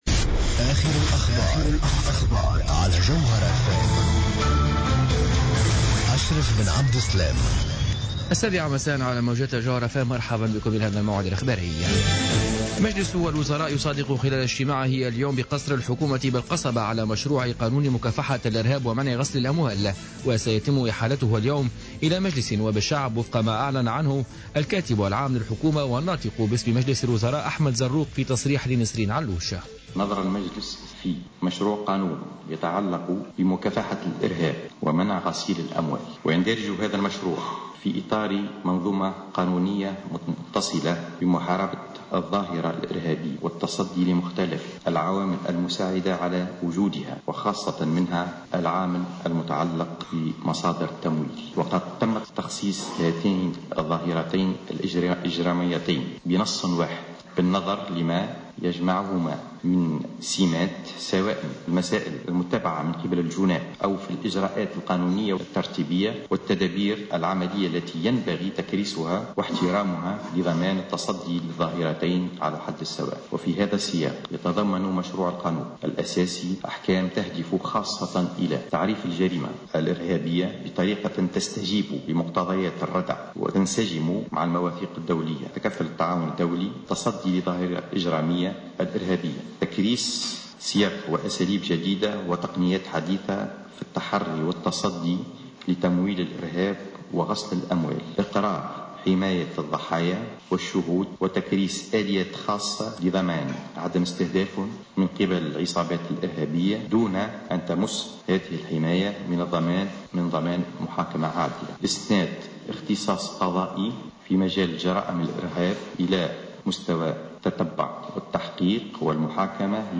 نشرة أخبار السابعة مساء الاربعاء 25 مارس 2015